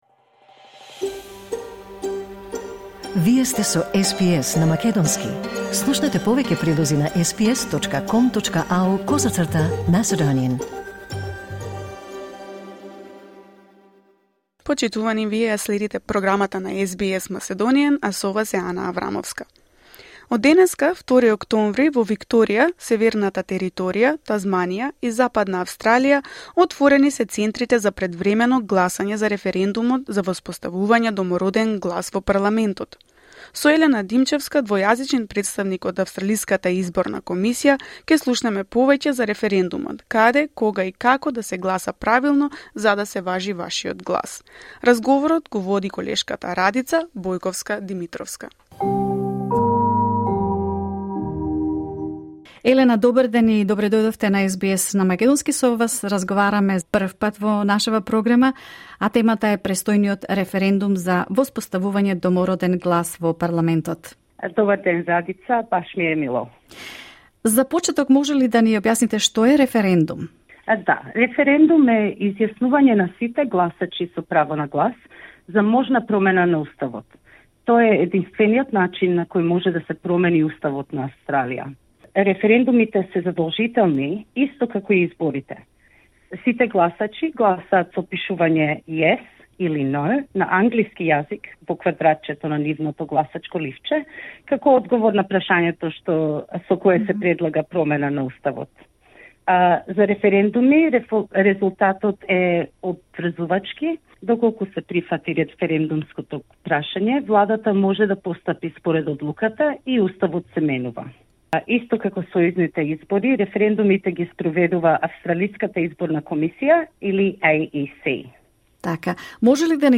Гласањето на референдумот за воспоставување Домороден глас во парламентот е задолжително. Слушнете од разговорот